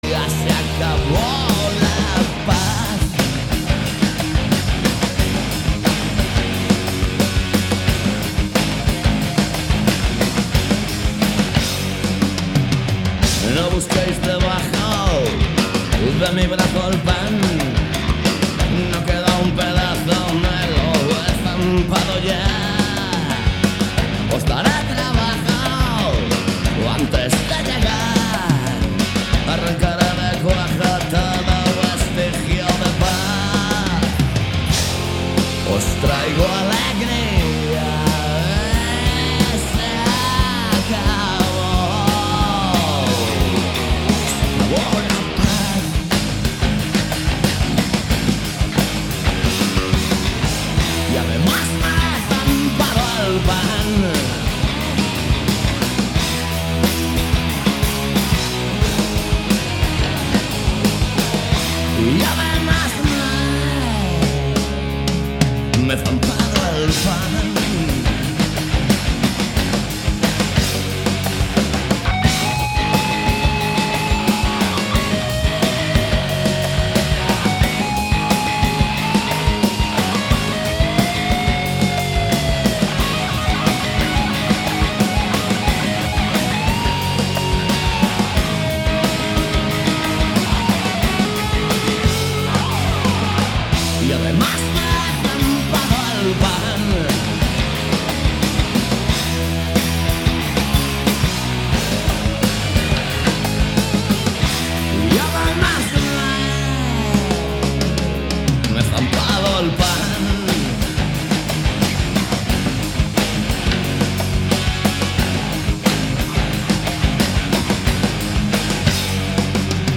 Todo iso misturado con boa música e un pouco de humor se o tema o permite. Cada martes ás 18 horas en directo.
Programa emitido cada mércores de 19:00 a 20:00 horas.